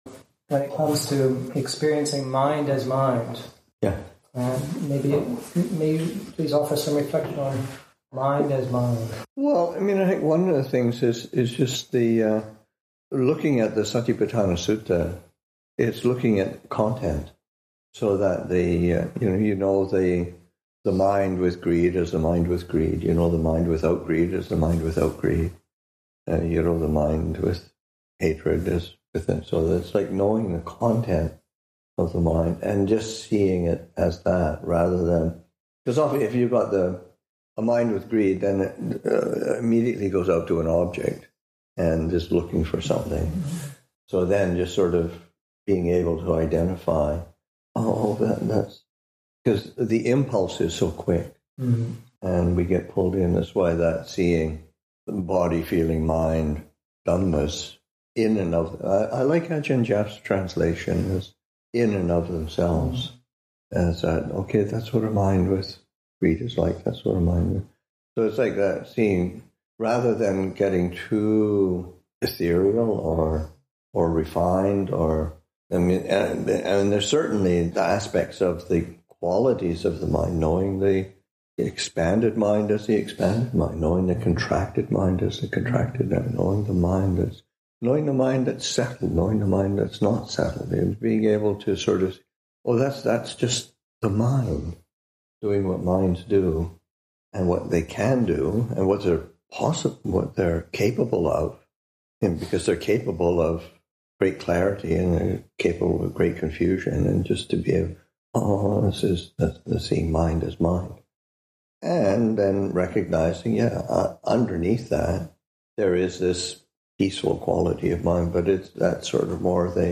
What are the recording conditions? Kathina Q&A with the Chithurst Community [2025], Session 1, Excerpt 2.1